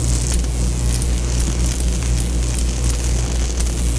weapon_lightning_006.wav